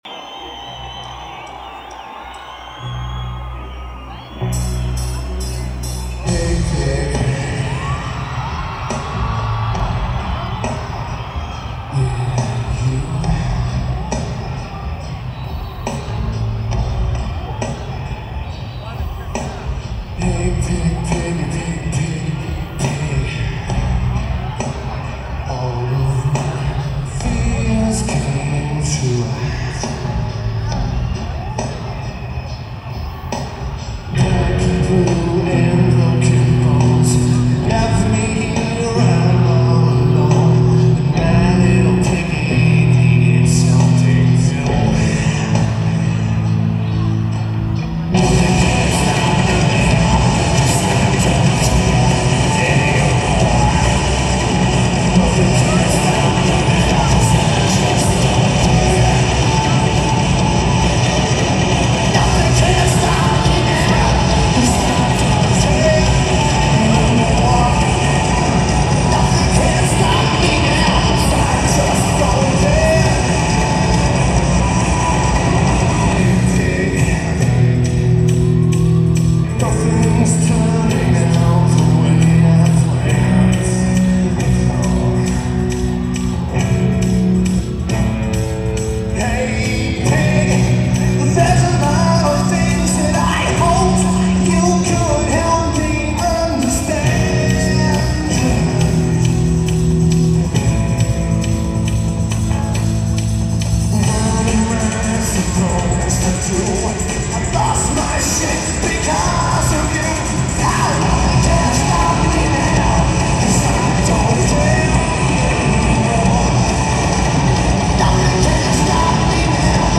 Toronto, ON Canada